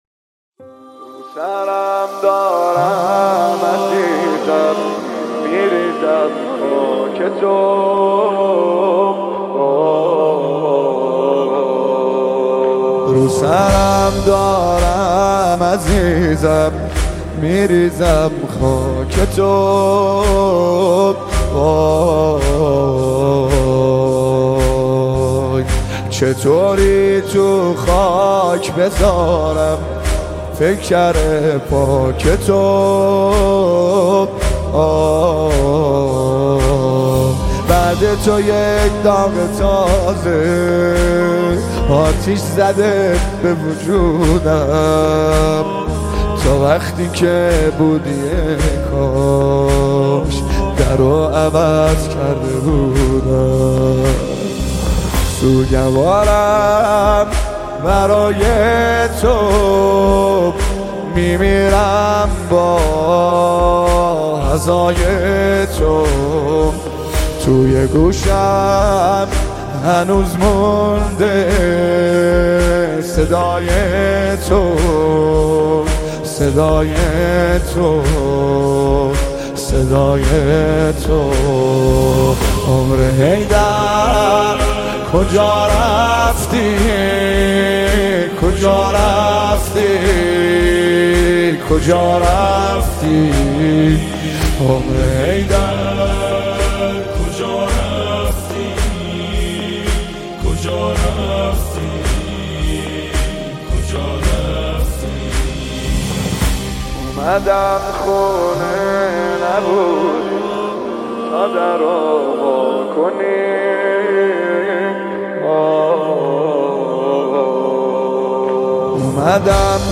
نماهنگ جدید فاطمیه
مداحی فاطمیه